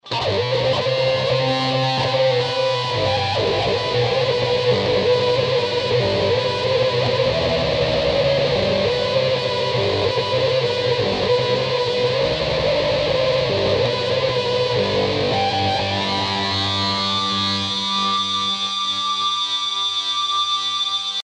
На демонстрационных записях использовались только пресетные (не изменяемые пользователем) настройки.
Начну с удивившего меня Ринг-Модулятора.
Заодно услышите удачный пресет для соло.
ring_mod.mp3